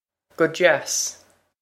guh jass.
This is an approximate phonetic pronunciation of the phrase.